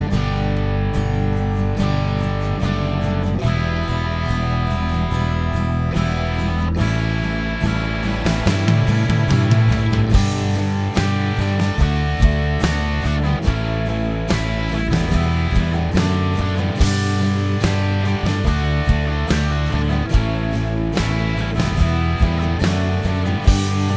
Minus Lead Guitar Indie / Alternative 4:02 Buy £1.50